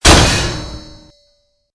CHQ_FACT_stomper_med.ogg